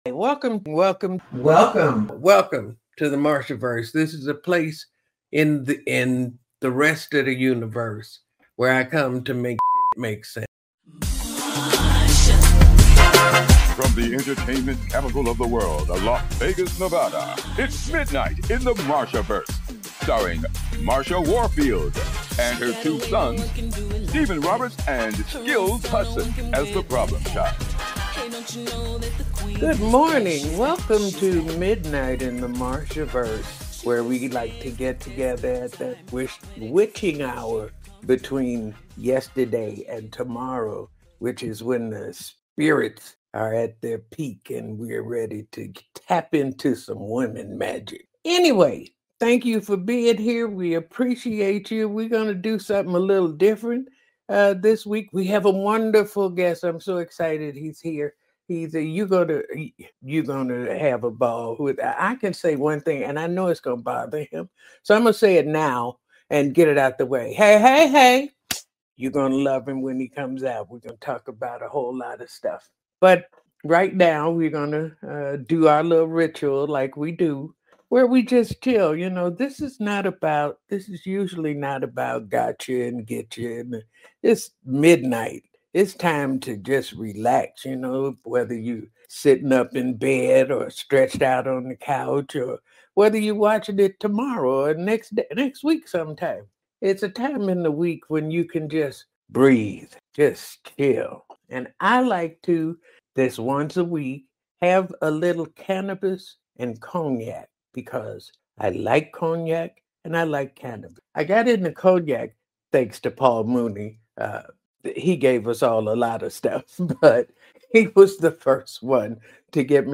an unforgettable conversation. From his groundbreaking career as a child actor in the 60s to his continued influence today, Nelson shares wisdom, resilience, and humor. The crew also unpacks President Trump’s recent speech to top-ranking generals and admirals, reflecting on America’s quizzical political climate.